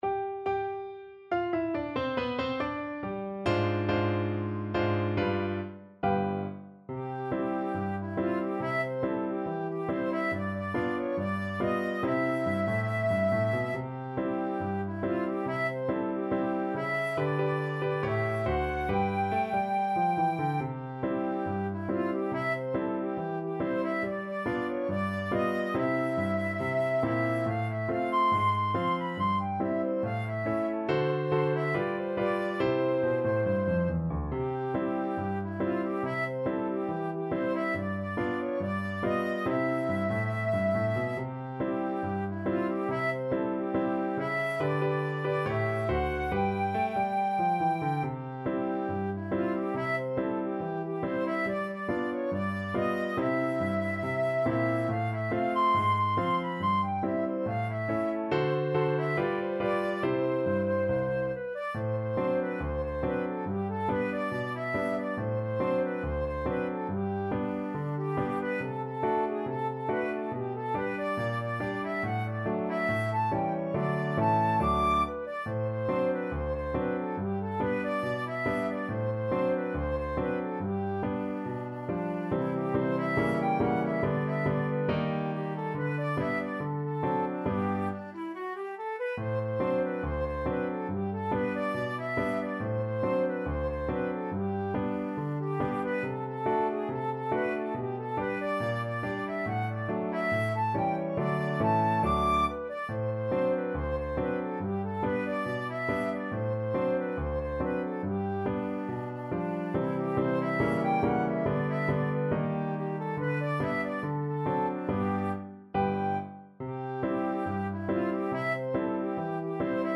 Flute
C major (Sounding Pitch) (View more C major Music for Flute )
2/4 (View more 2/4 Music)
Not fast Not fast. = 70
Jazz (View more Jazz Flute Music)